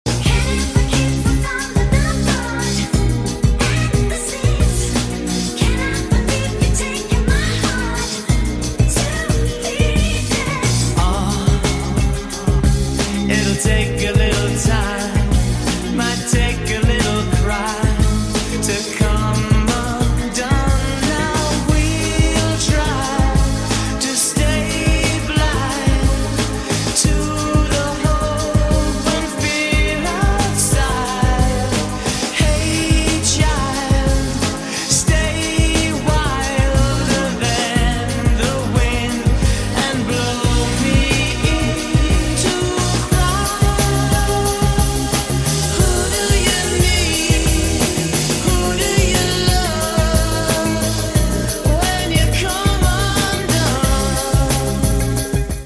Bismillah. Dijual Harley Davidson Electra sound effects free download
Dijual Harley Davidson Electra Mp3 Sound Effect Bismillah. Dijual Harley Davidson Electra Police Panda 1999 (FLHTP) KARBURATOR Full Paper (STNK, BPKB, FAKTUR) aman, nyaman, tenang diperjalanan.